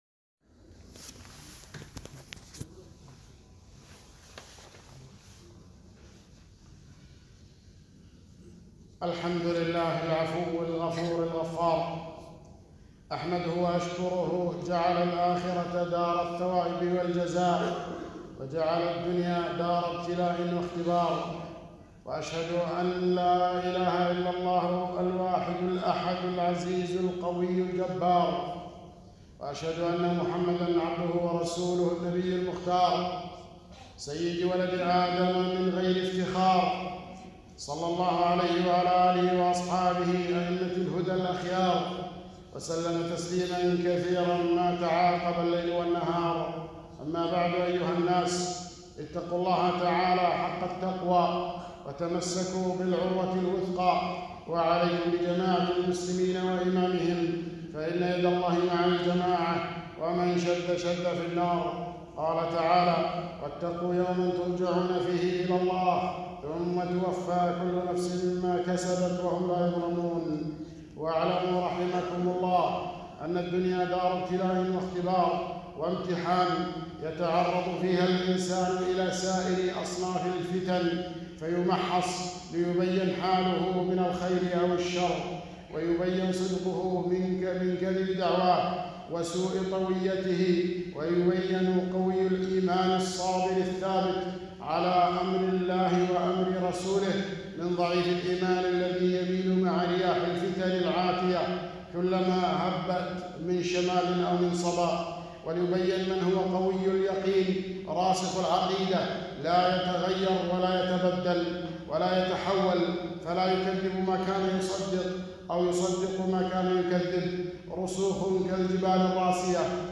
خطبة - كيف النجاة من الفتن؟